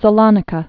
(sə-lŏnĭ-kə, sălə-nēkə)